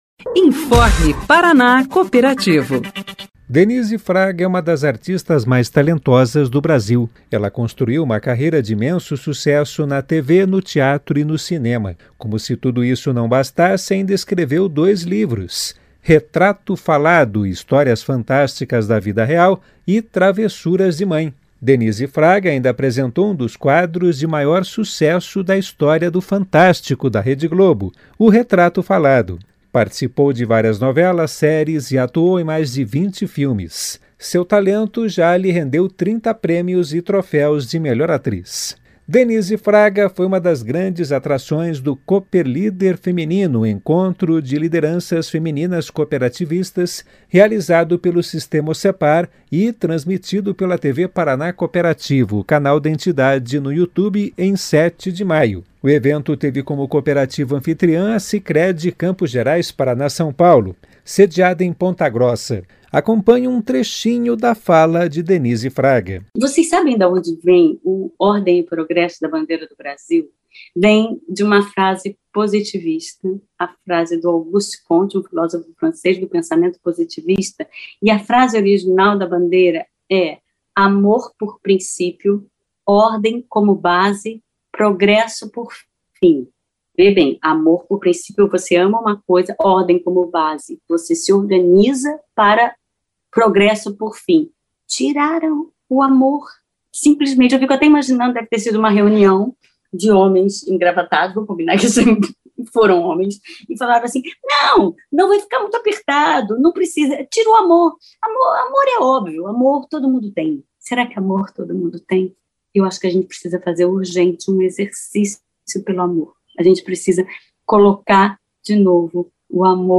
Denise Fraga foi uma das grandes atrações do "Cooper Líder Feminino - Encontro de Lideranças Femininas Cooperativistas", realizado pelo Sistema Ocepar e transmitido pela TV Paraná Cooperativo, o canal da entidade no YouTube, em 07/05.
Acompanhe um trechinho da fala de Denise Fraga.